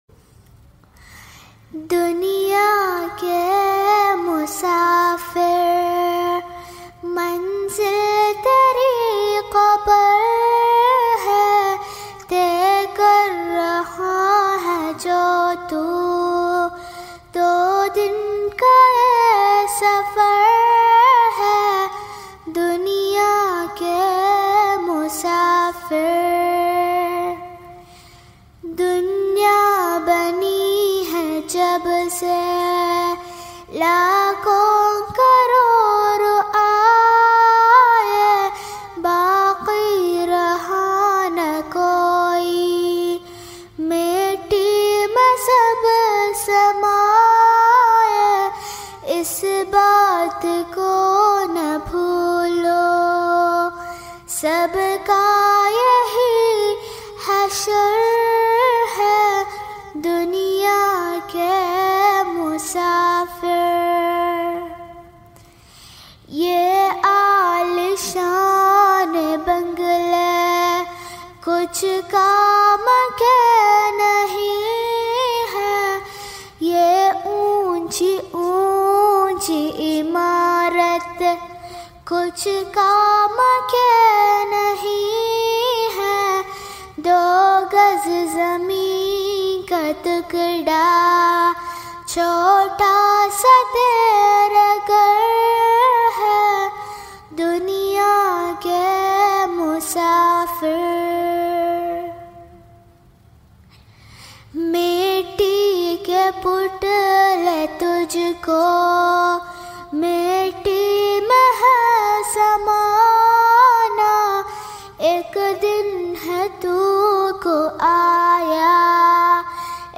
• Speaker: Singer